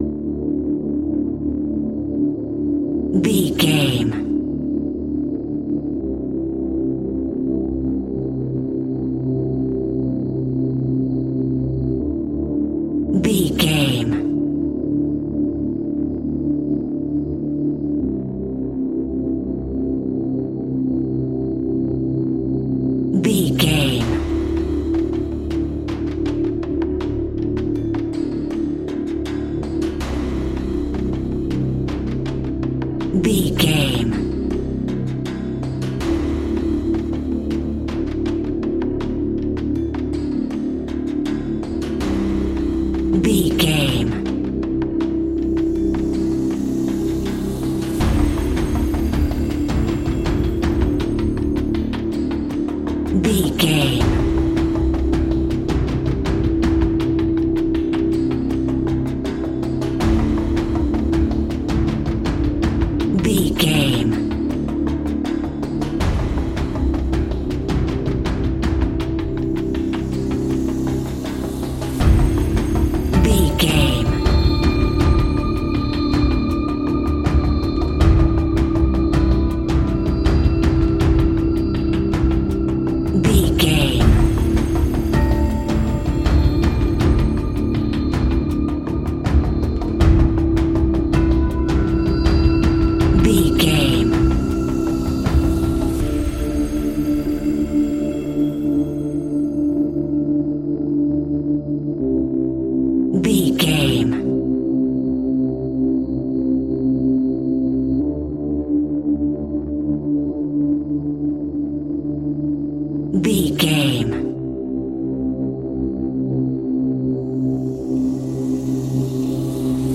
In-crescendo
Aeolian/Minor
scary
ominous
dark
haunting
eerie
ticking
electronic music
Horror Pads
horror piano
Horror Synths